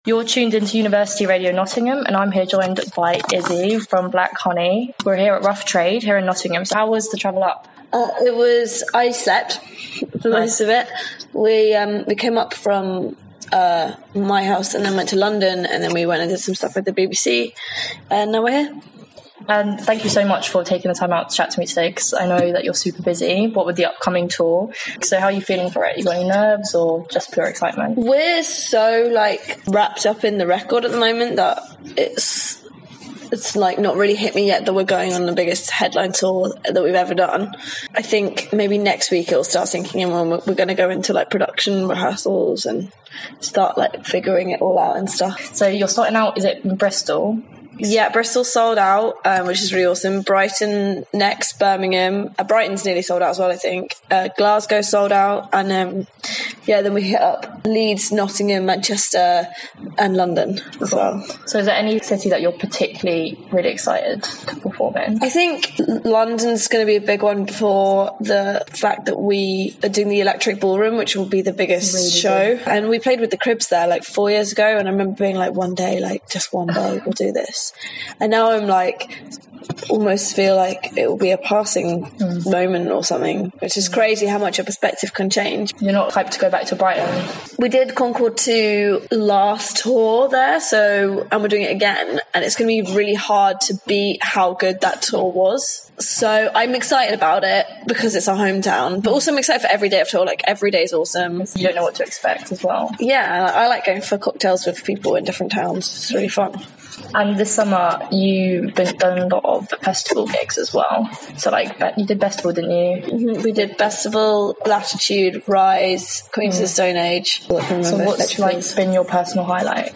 Black Honey Interview